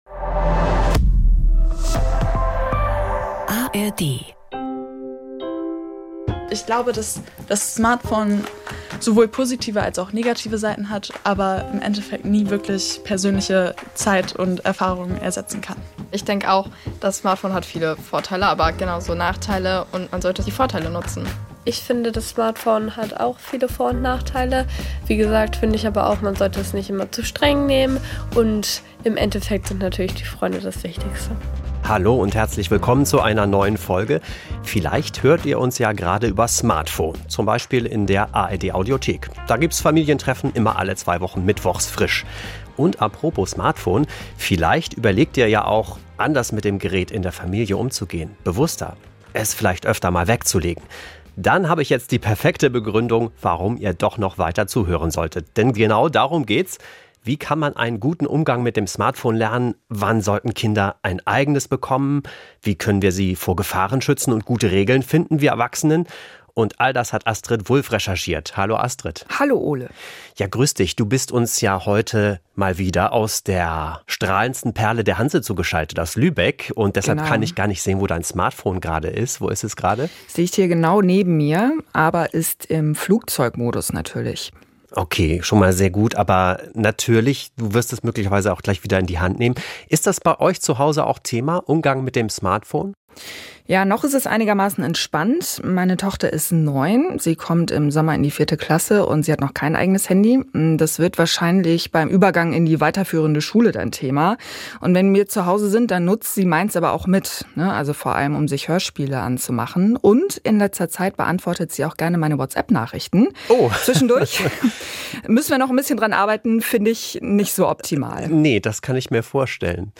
Allerdings schaffen es auch die Erwachsenen nicht immer, gute Vorbilder zu sein. Wie finden wir gute Verabredungen und einen entspannten Umgang mit den Smartphones? Wir sprechen mit Familien und einer Medienexpertin darüber.